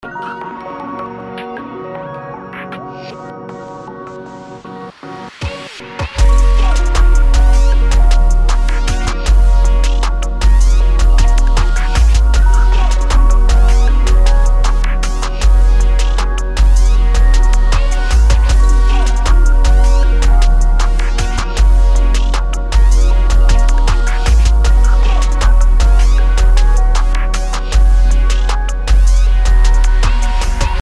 BPM: 156
Key: Eb minor
Preview del beat: